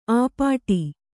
♪ āpāṭi